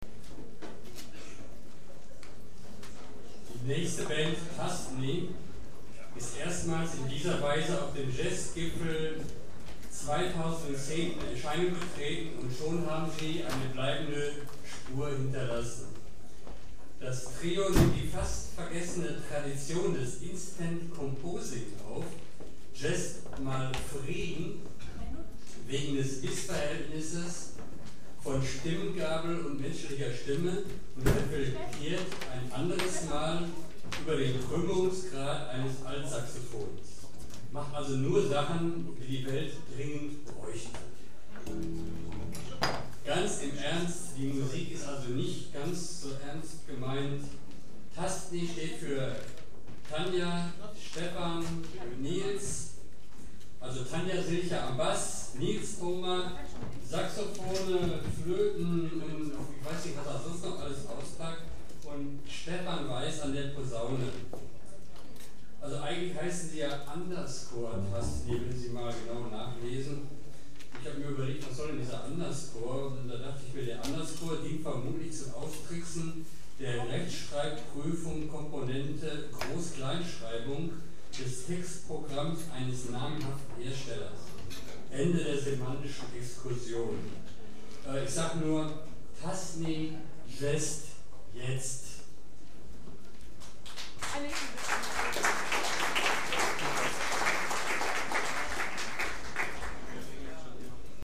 1 Moderation [1:41]
00 - Moderation.mp3